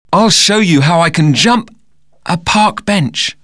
Dans le dialogue, un mot n'est pas audible.